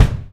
Tuned drums (G key) Free sound effects and audio clips
• Rich Mids Kick Sample G Key 17.wav
Royality free kick drum tuned to the G note. Loudest frequency: 311Hz
rich-mids-kick-sample-g-key-17-xSe.wav